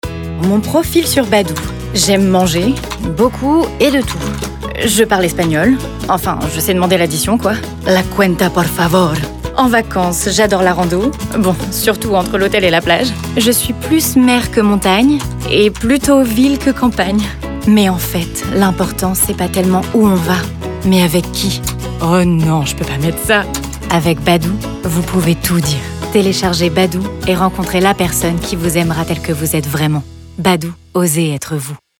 Démo voix pub
Voix off